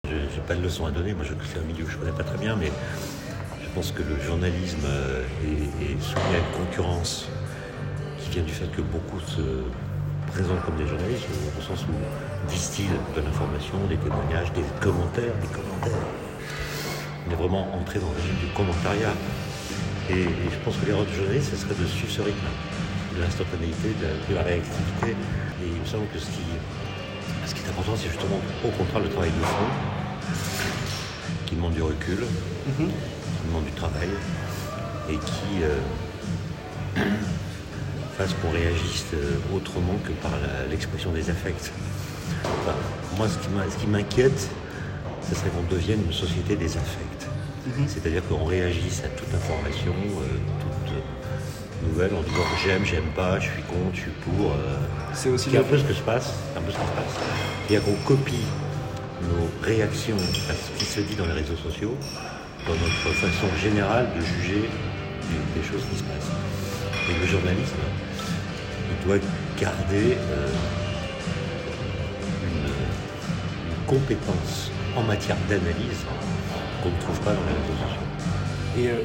Cette propension à s’exprimer tout le temps, sur tous les sujets, sans réelles compétences mais avec un aplomb affirmé est résumée par Etienne Klein, philosophe des sciences et vulgarisateur scientifique, à travers le terme d’ultracrépidarianisme. Interrogé, il donne son avis sur le niveau du débat public et la responsabilité des journalistes et des éditorialistes :
Etienne-Klein-enquete.m4a